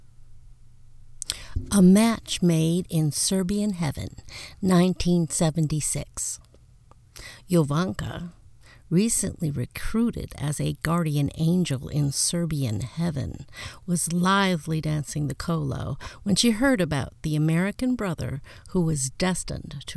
I re-recorded that sample
I put the room noise back in the beginning.